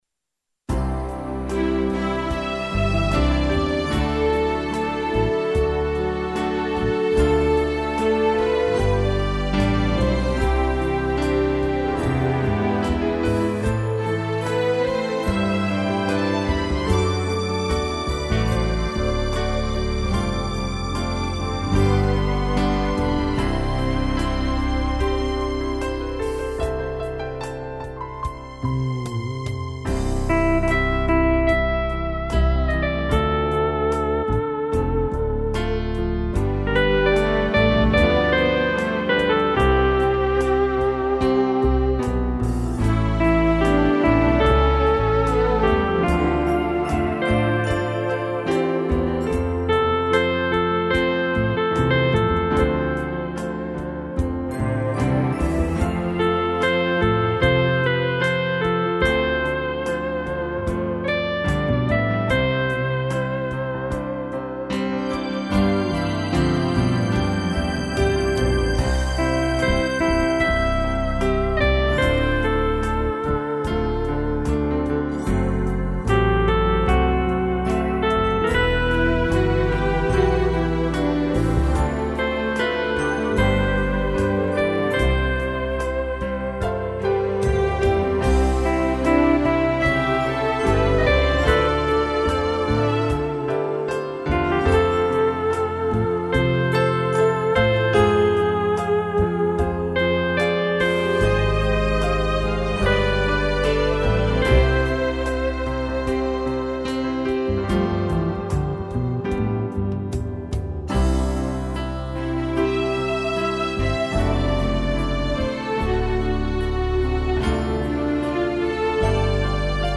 piano
arranjo e intepretação teclado